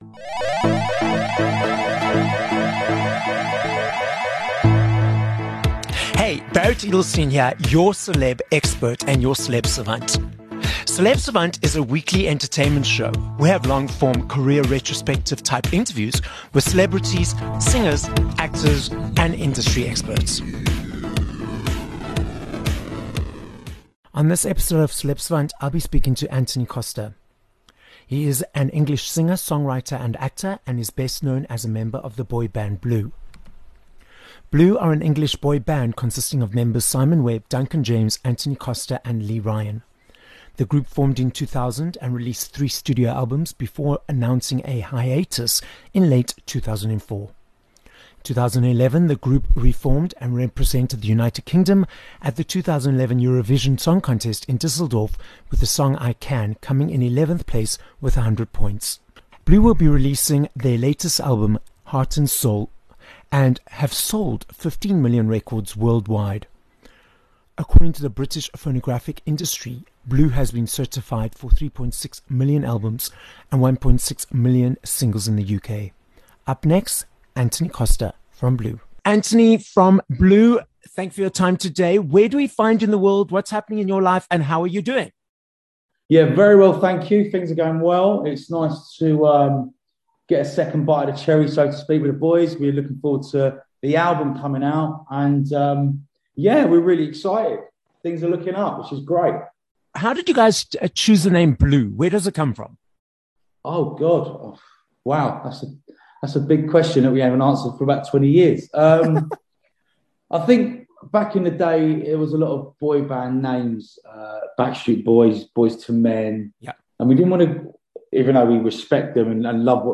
24 Oct Interview with Antony Costa (Blue)